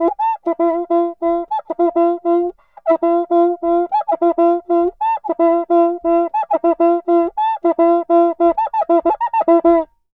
Cuica_Samba 100_2.wav